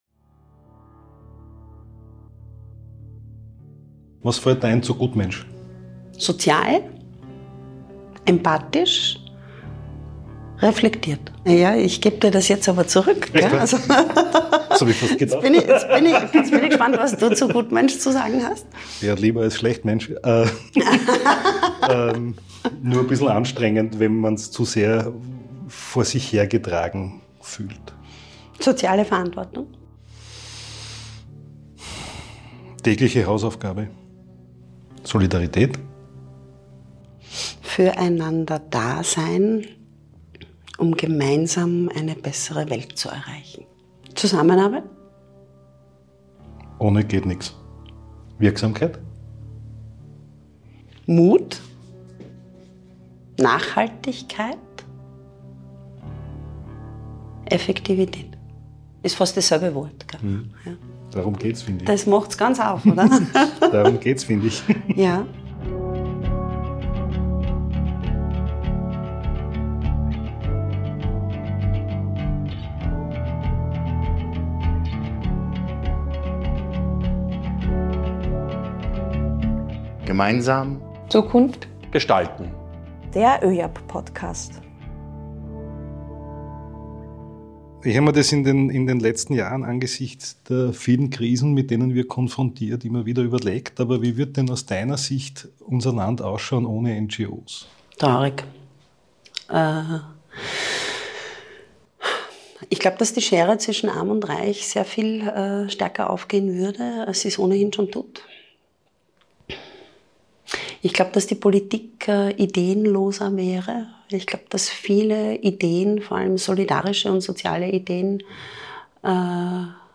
Ein Gespräch über Chancen und Herausforderungen des gemeinnützigen Sektors, bei dem auch Forderungen und Voraussetzungen für die eigene Wirksamkeit formuliert werden.